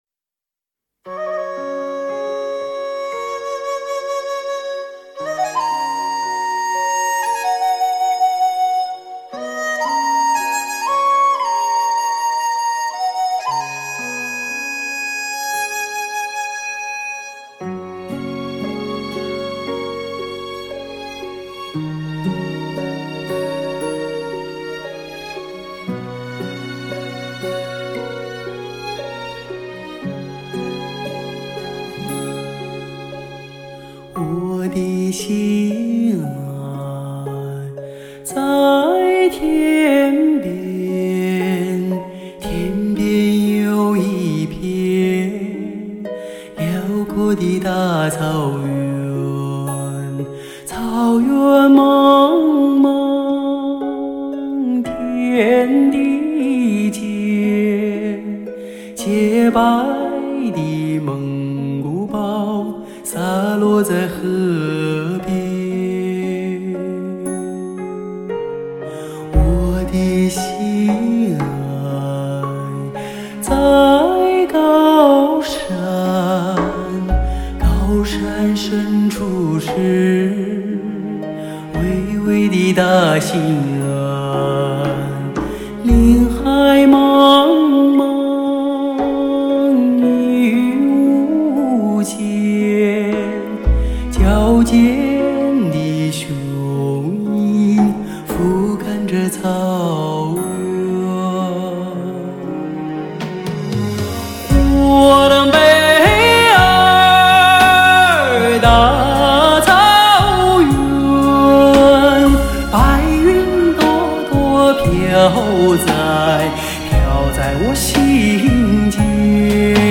最HIFI的魅力男女靓声